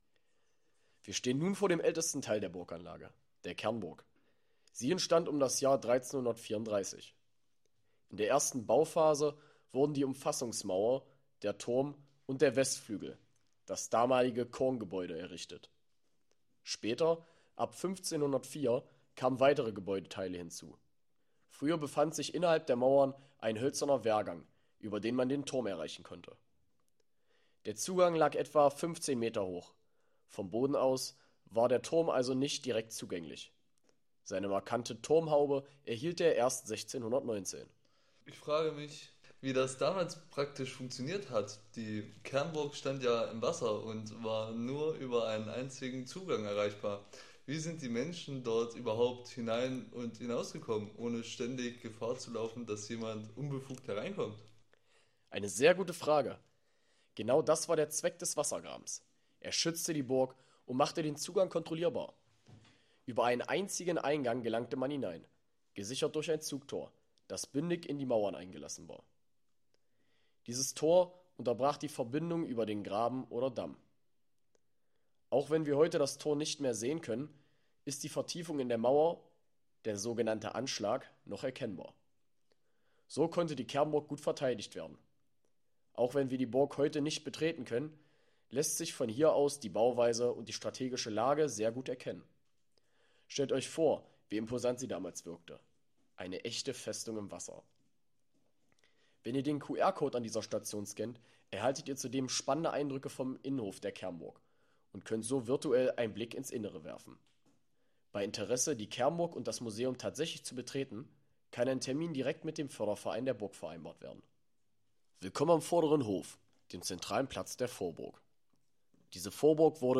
Diese Audiotour begleitet Sie durch Geschichte und Besonderheiten der Anlage.